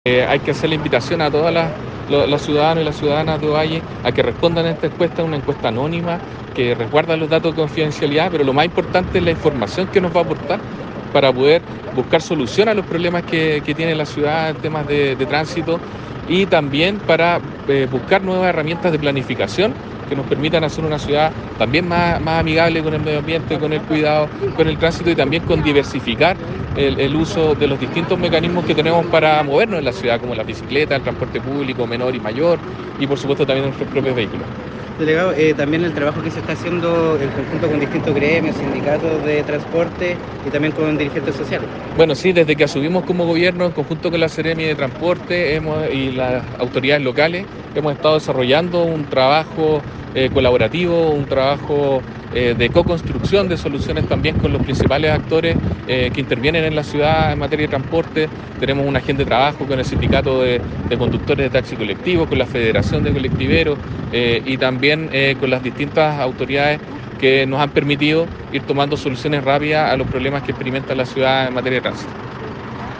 El Delegado presidencial provincial de Limarí, Galo Luna Penna, hizo un llamado a colaborar con el desarrollo de esta EOD e indicó que este instrumento es parte de un trabajo constante que se está realizando en esta materia, señalando que
DPP-LIMARI-GALO-LUNA-PENNA.mp3